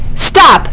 haptic, haptic warning, auditory warning, multi-modality warning, in-vehicle warning, intersection violation, brake pulse warning